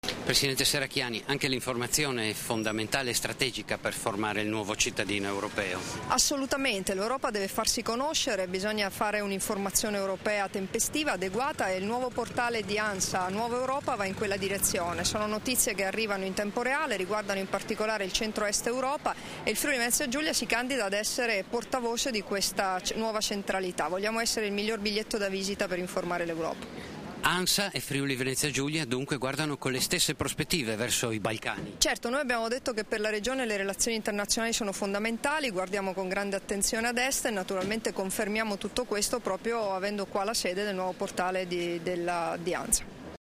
Dichiarazioni di Debora Serracchiani (Formato MP3) [693KB]
alla presentazione del portale "ANSA Nuova Europa", rilasciate a Trieste il 16 settembre 2013